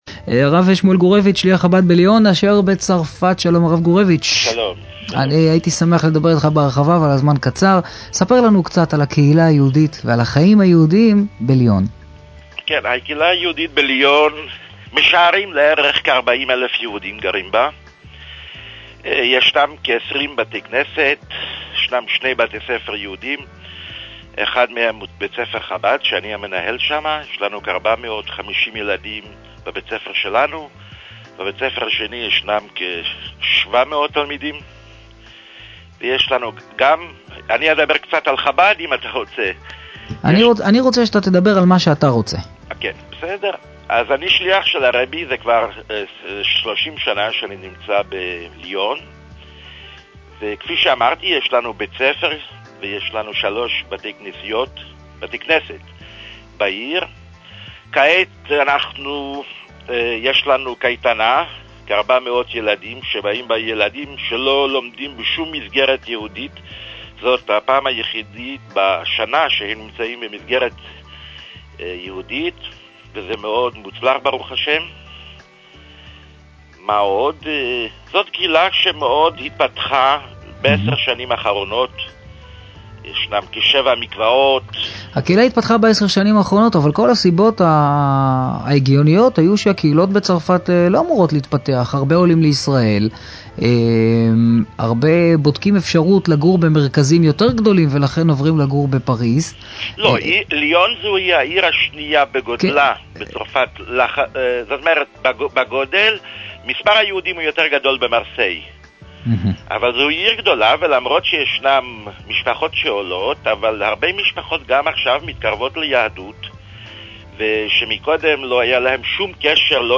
היה אורח הפינה הקבועה של ראיון עם שליח חב"ד